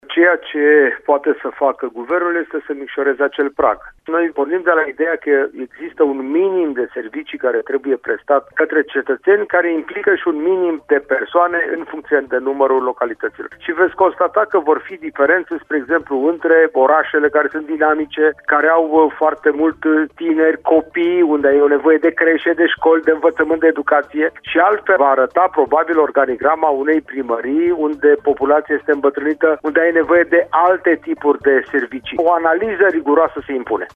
Potrivit secretarului general al Guvernului, socia-democratul Radu Oprea, în cea ce priveşte această reformă a administraţiei locale, mai este nevoie şi de analize în fiecare localitate în parte, pentru că acestea au componente demografice diferite: